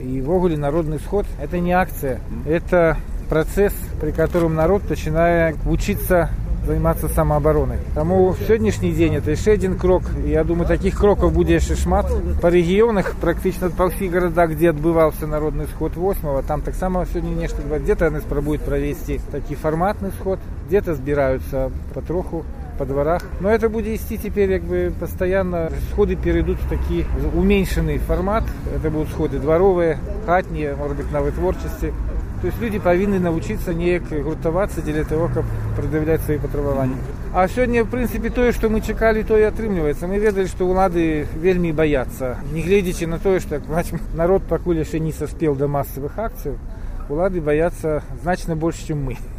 Камэнтар